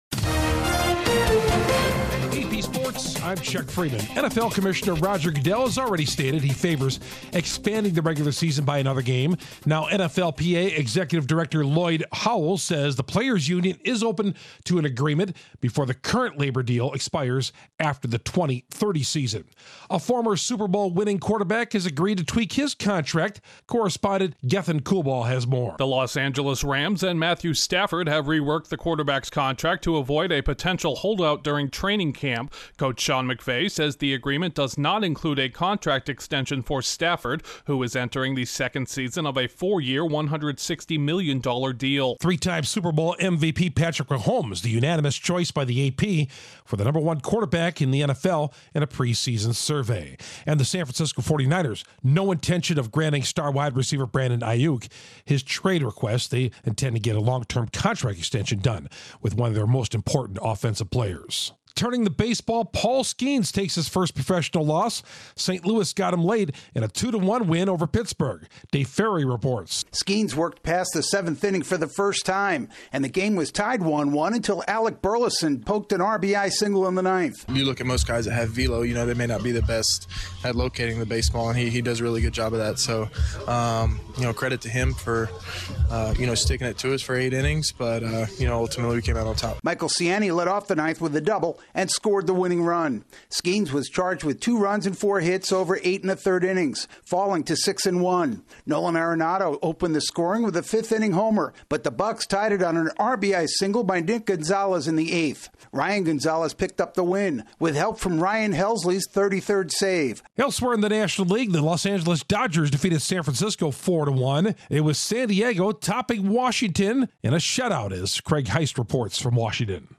The NFL eyes an 18 game schedule in the future, Chief's Patrick Mahomes is tops, Pirate's Paul Skenes suffers his first loss, and the Mets take down the Yanks. Correspondent